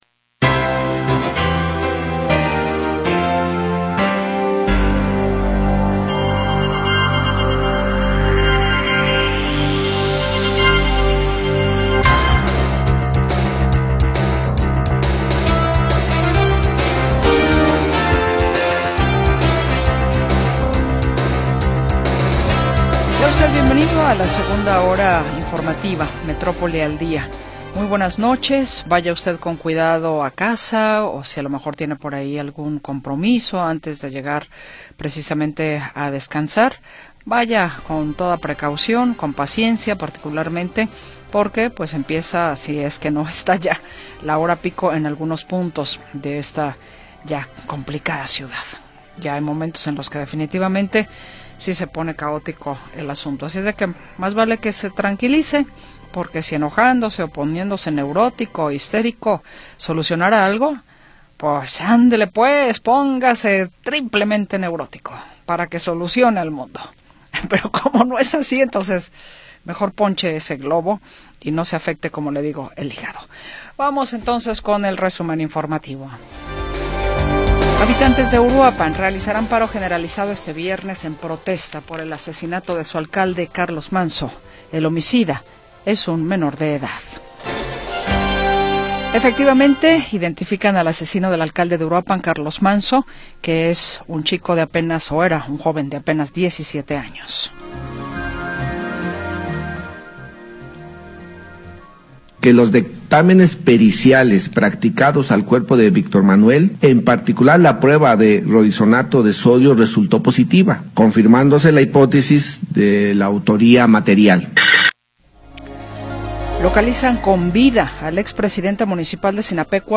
Segunda hora del programa transmitido el 6 de Noviembre de 2025.